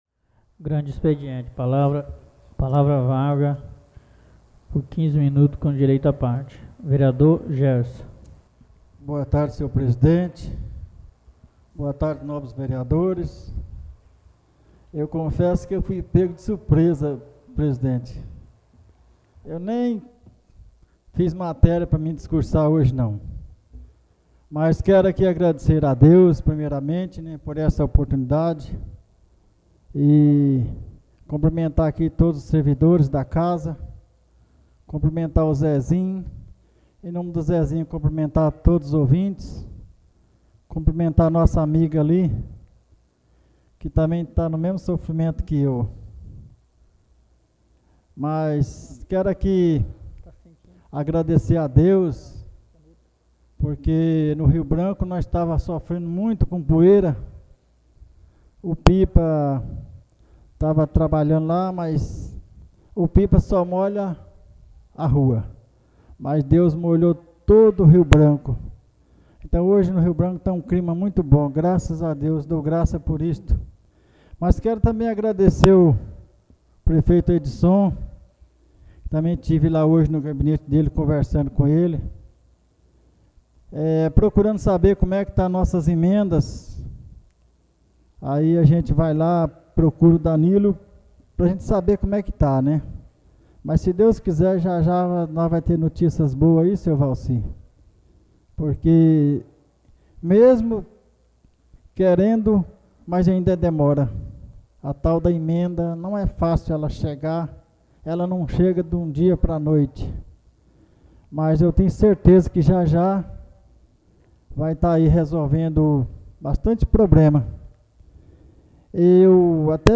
48ª sessão ordinária